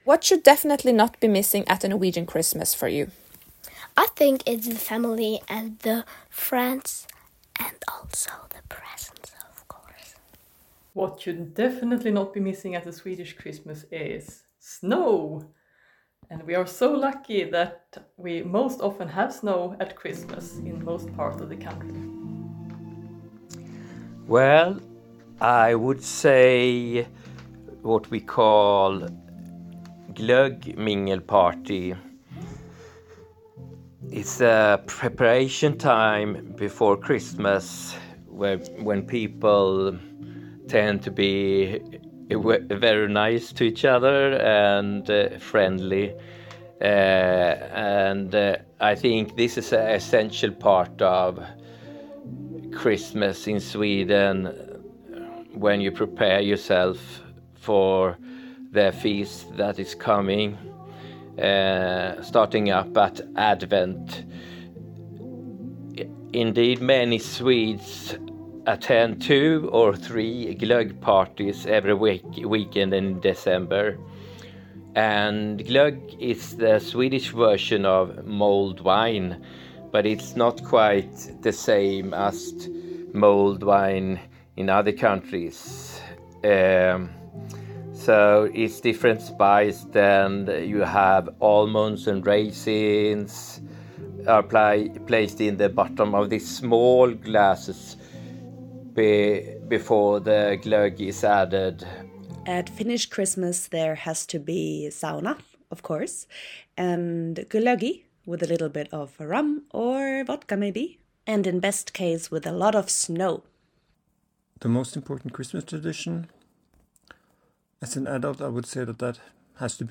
In dieser letzten Folge für dieses Jahr sprechen wir über skandinavische und finnische Weihnachtstraditionen. Und außerdem kommen die Norweger, Schweden und Finnen in dieser Folge selbst zu Wort.